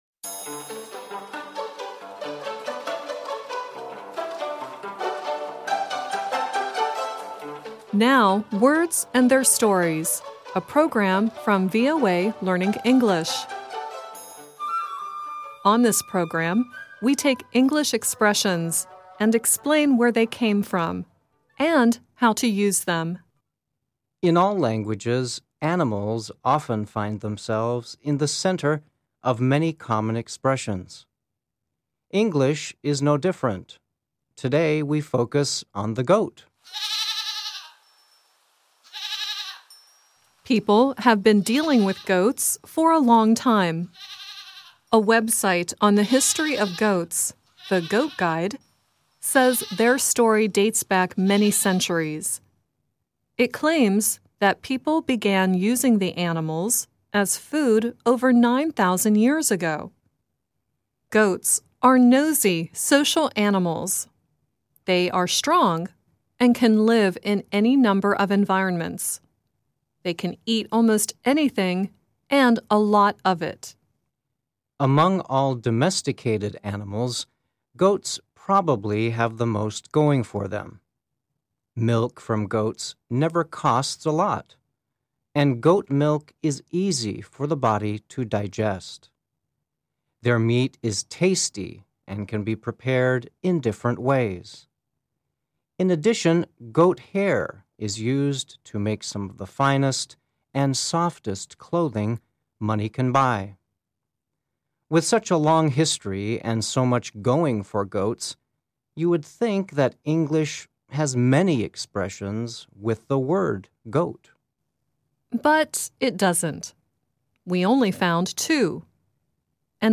The song at the end is Devo singing “Gut Feeling.